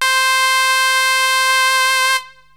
SYNTH GENERAL-4 0010.wav